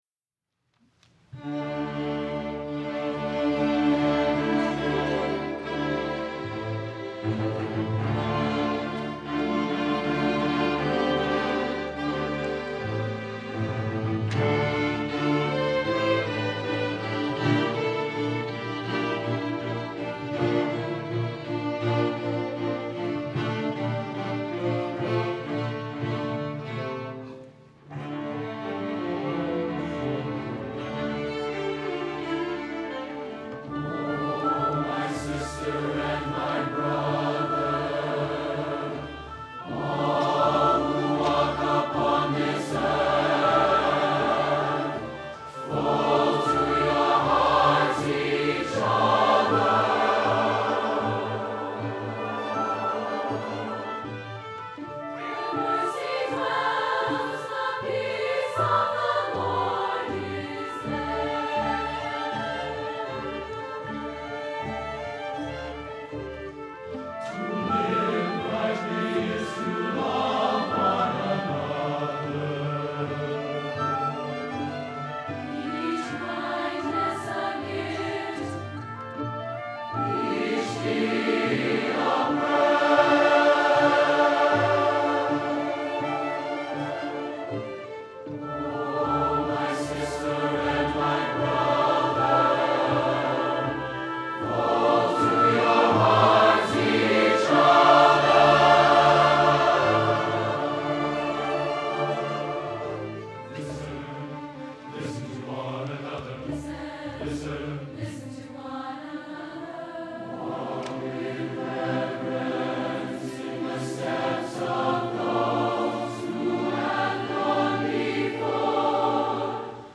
for SATB Chorus and Chamber Orchestra (2004-07)
The work opens with accented chords in the strings, clarinet, bassoon, and timpani.
The tender aspects are introduced in the arpeggio patterns in the piano.